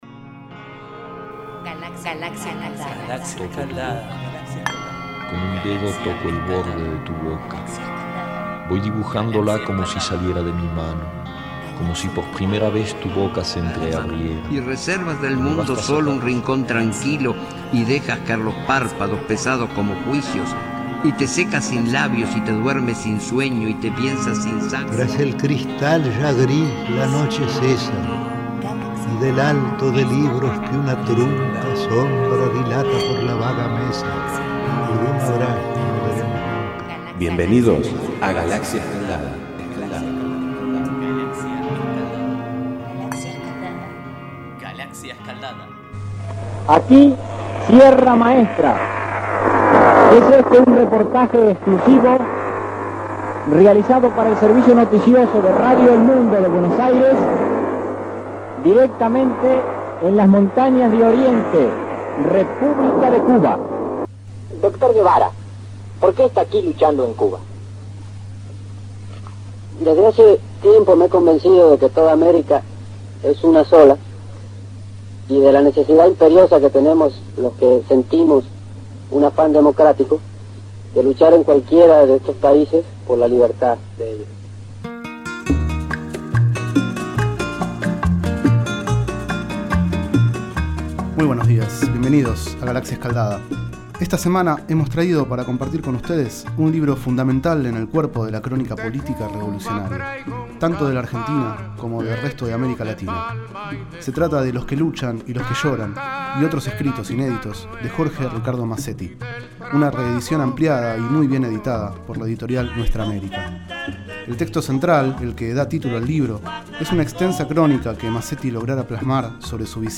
Este es el 29º micro radial, emitido en los programas Enredados, de la Red de Cultura de Boedo, y En Ayunas, el mañanero de Boedo, por FMBoedo, realizado el 29 de septiembre de 2012, sobre el libro Los que luchan y los que lloran,  de Jorge Ricardo Masetti.
Durante el micro escuchamos un breve fragmento del reportaje realizado en Sierra Maestra por Masetti al Che, donde, como escribe el propio Masetti (pág. 138) ante su pregunta de «¿Por qué está aquí, doctor Guevara?, la respuesta fué pronunciada con la calma con que había sido dada anteriormente y con una tonada indefinida«.
Y luego suena De Cuba traigo un cantar, de Carlos Puebla.